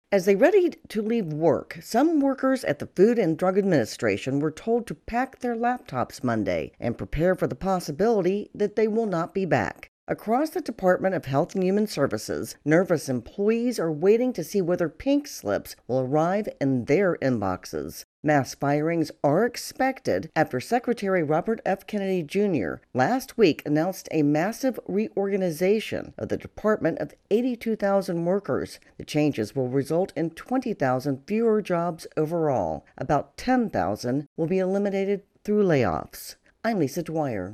reports on staffing cuts at the FDA.